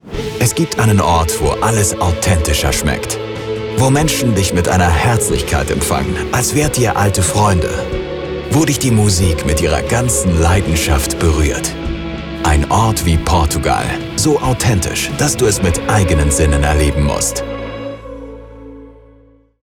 German Super Bock TVC
An authentic, real narrative voice.
Hochdeutsch Werbung Sample.mp3